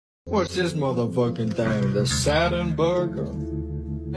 Burger Sound Effects MP3 Download Free - Quick Sounds